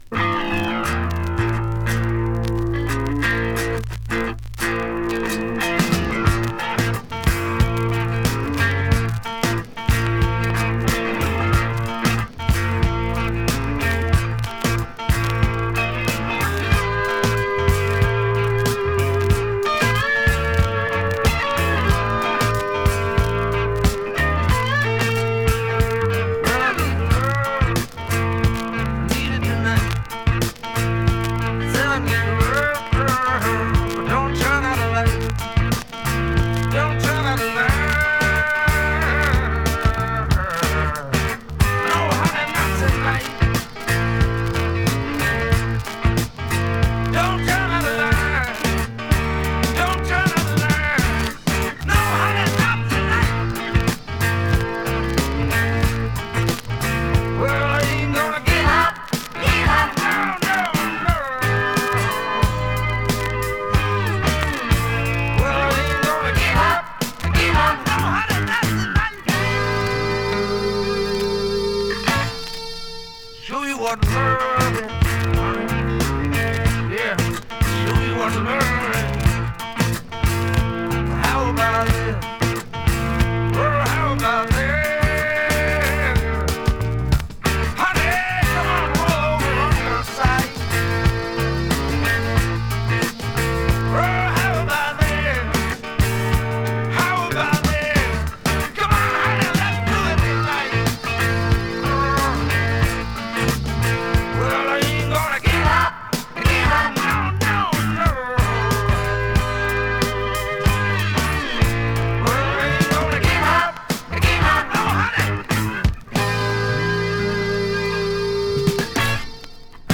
Dutch Mellow Boogie! オランダの男女デュオ。
【A.O.R.】
VG/VG 薄いスリキズによるごく僅かなチリノイズ sleeve